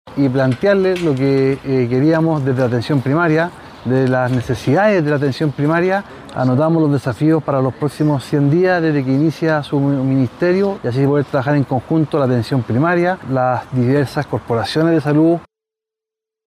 En ese contexto, Alessandri explicó que en la reunión se abordaron los principales problemas que enfrentan los municipios en materia de salud y valoró la disposición de la futura ministra para escuchar los planteamientos de los alcaldes.